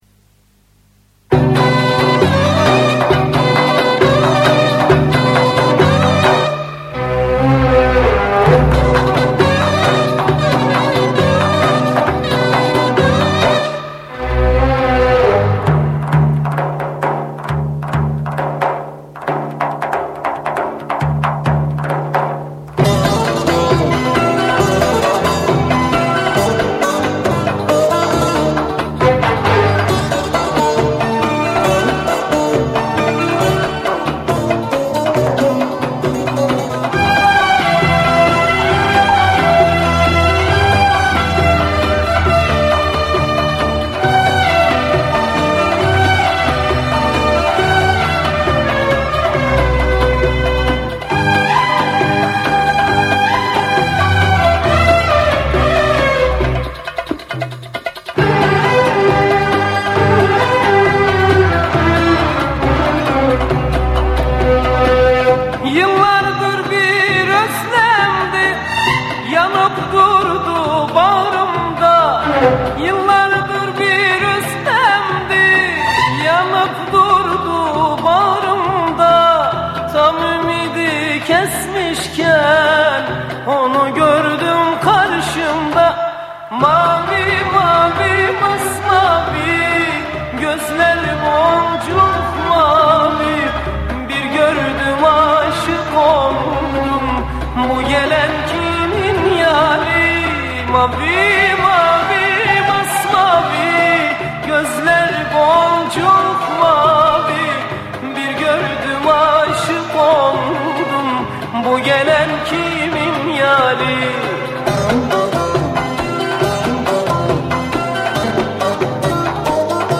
عربسک، موسیقی سنتی ترکی